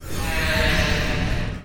mana_burn.mp3